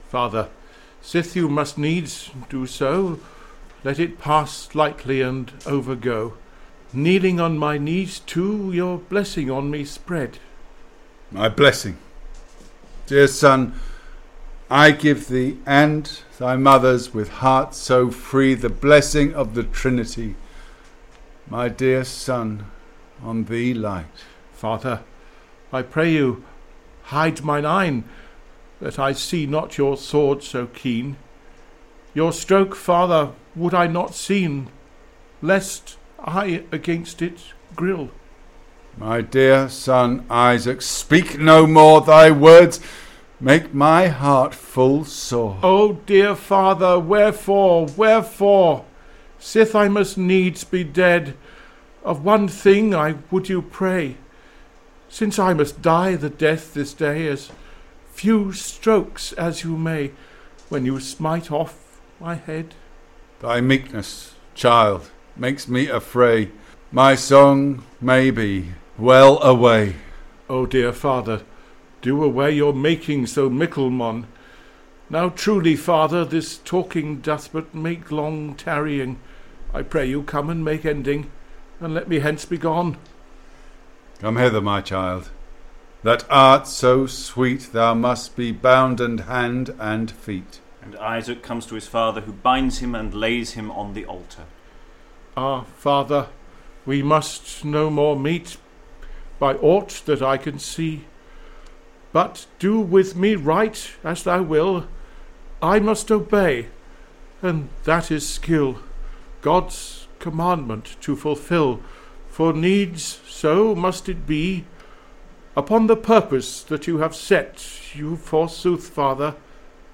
Exploring the Chester Mystery Plays is a series of live streamed events where the Chester plays are taken apart with readers and commentary. Rough round the edges, edited versions of these events are now being posted online. This post covers the middle section of play 4, where Abraham prepares to sacrifice Isaac.